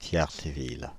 Tierceville (French pronunciation: [tjɛʁsəvil]
Fr-Paris--Tierceville.ogg.mp3